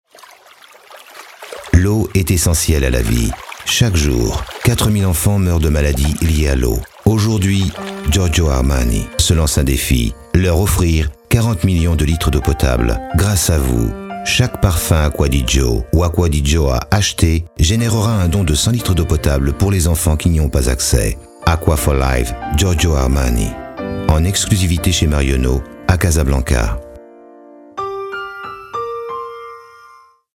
Pymprod réalise pour vous des spots publicitaires avec nos voix off masculines et féminines.
Pymprod a réalisé un spot publicitaire pour le parfum Acqua for Life !